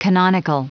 Prononciation du mot canonical en anglais (fichier audio)
Prononciation du mot : canonical